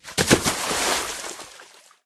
zombieEnteringWater.ogg